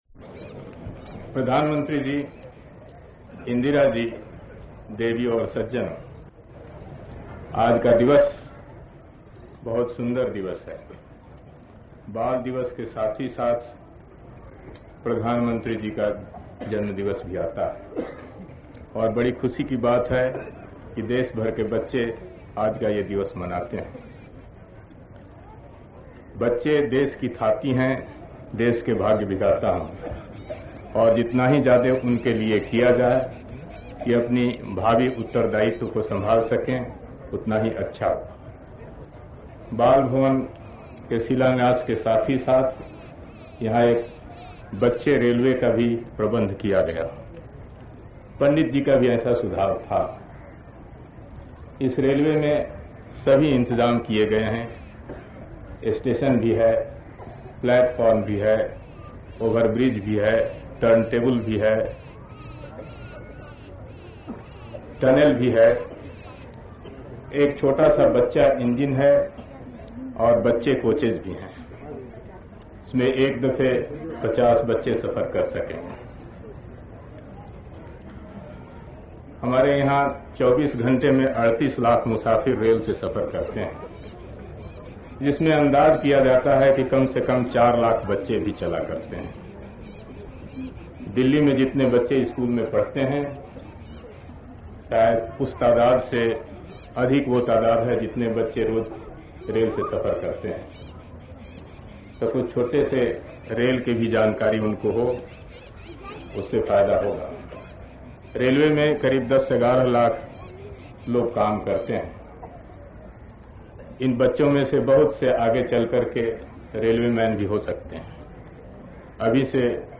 Audio speeches of Babu Jagjivan Ram outside Parliament
Speech at Bal Diwas - Jag Jivan Ram14-11-58